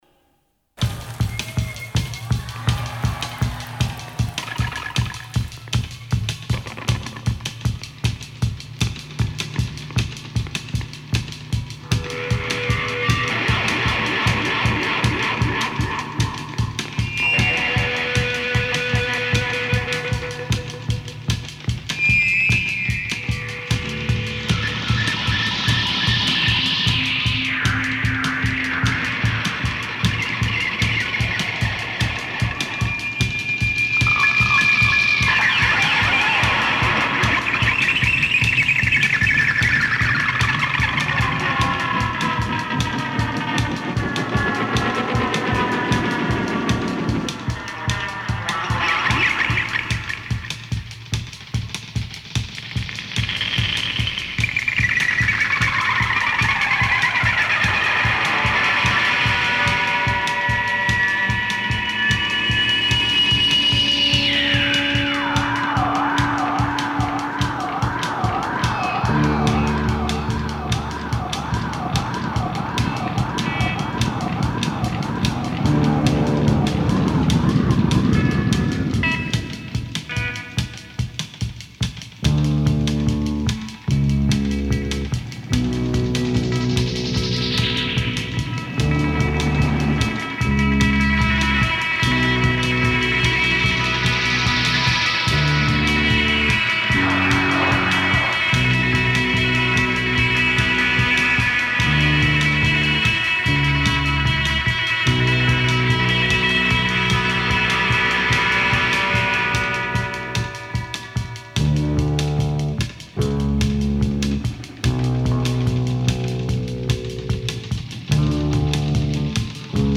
Vampire|Post-punk